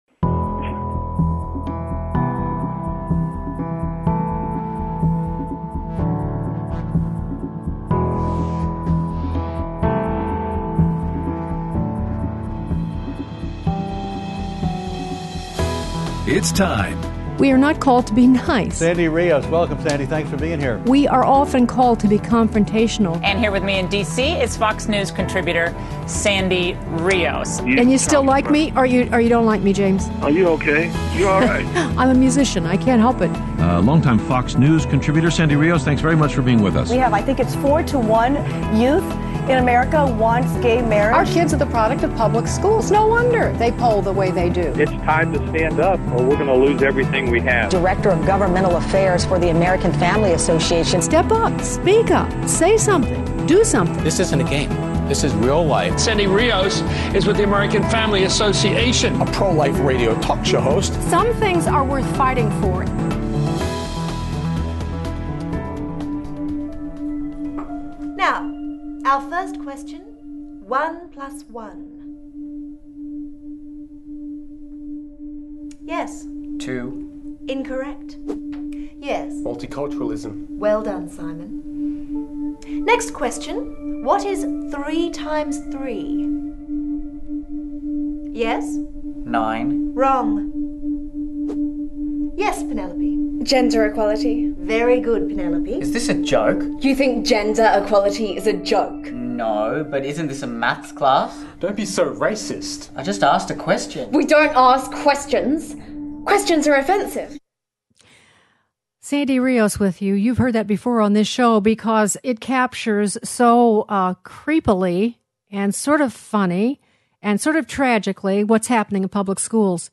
Aired Friday 4/27/18 on AFR 7:05AM - 8:00AM CST